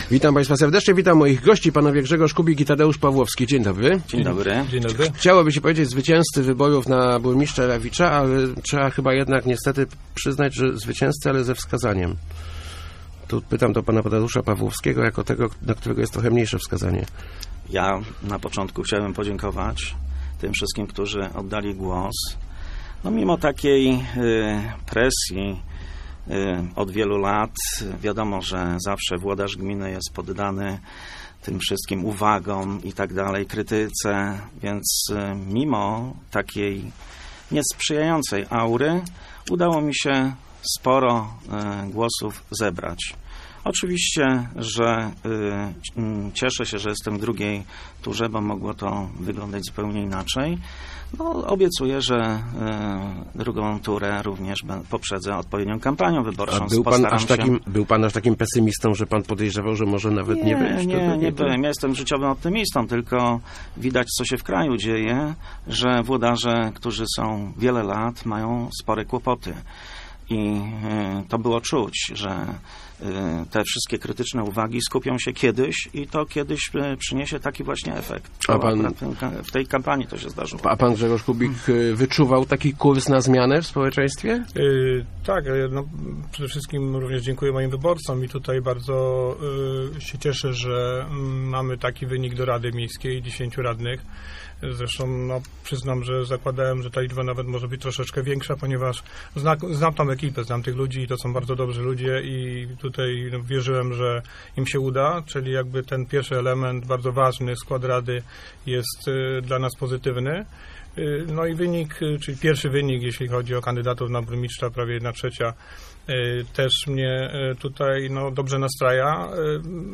Obecny burmistrz zapowiada, że w przez najbliższe dwa tygodnie będzi...